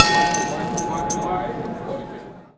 start auction.wav